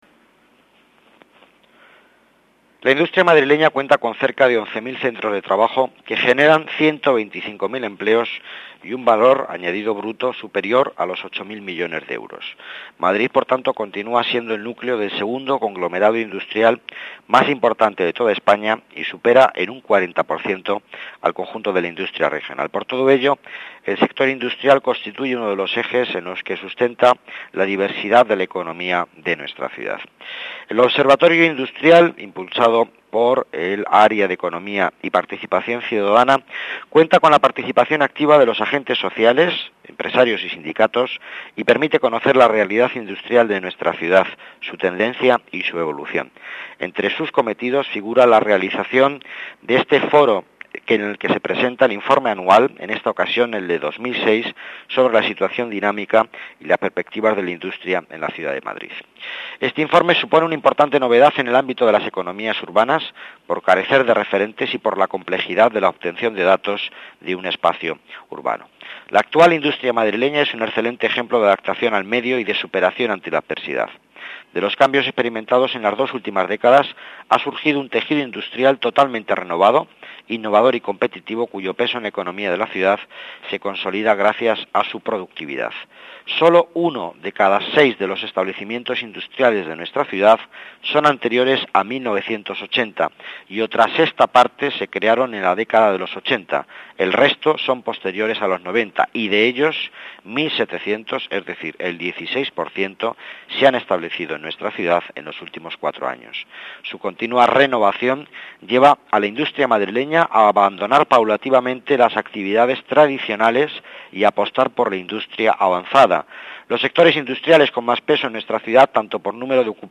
Nueva ventana:Declaraciones de Miguel Ángel Villanueva, consejero delegado de Economía, sobre el Informe de Economía urbana